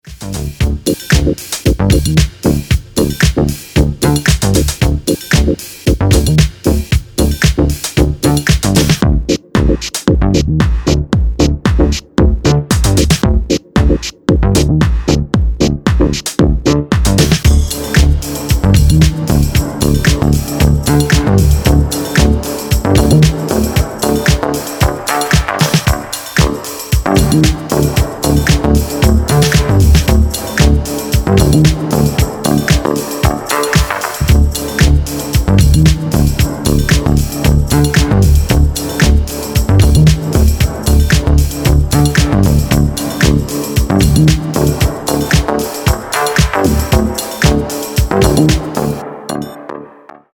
moody, mellow, but freaked out “Spaceship” Electrosongs
acid lines, disco breaks, rave synthesizers, drum machines
sleazy chilled out music